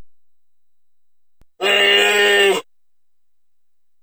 Dinosaur King Zuniceratops Bleat
Category: Sound FX   Right: Personal